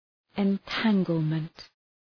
Shkrimi fonetik {ın’tæŋgəlmənt}